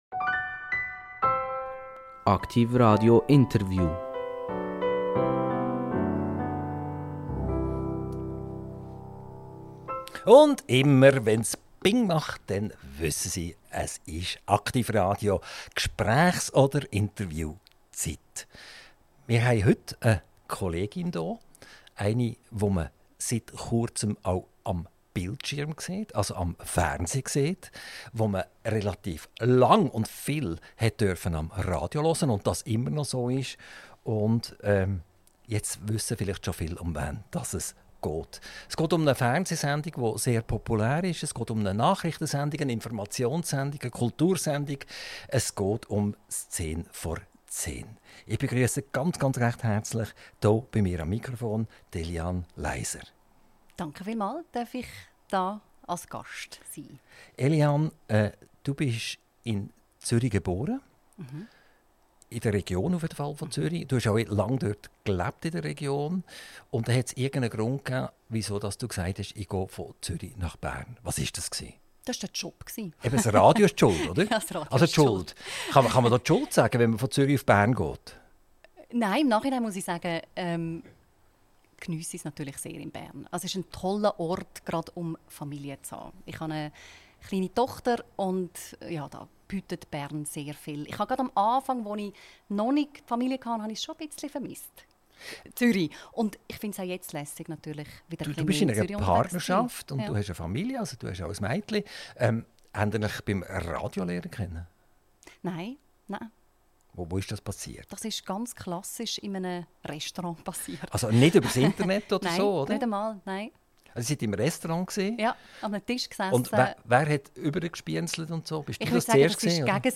INTERVIEW - Eliane Leiser - 20.08.2025 ~ AKTIV RADIO Podcast